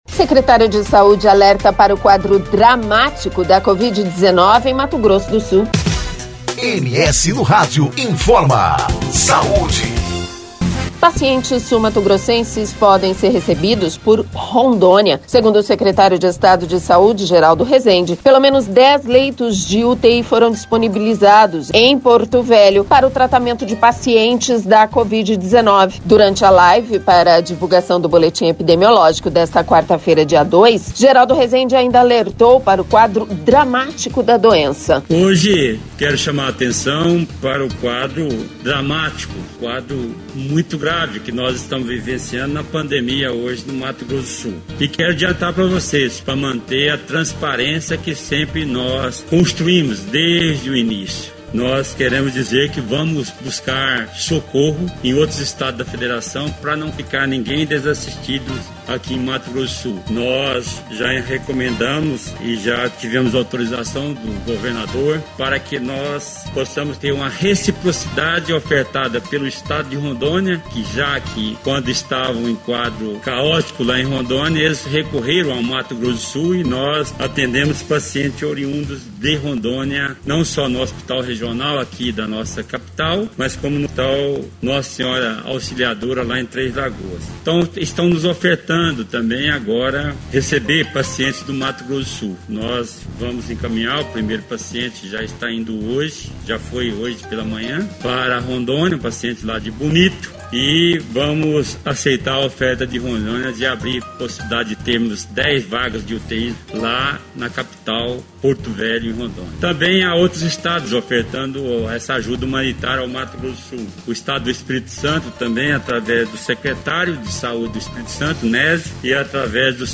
Segundo o secretário de saúde Geraldo Resende, pelo menos dez leitos de UTI foram disponibilizados em Porto Velho. Durante a live para divulgação do boletim epidemiológico desta quarta-feira, dia 02, Geraldo Resende ainda alertou para o quadro dramático da doença.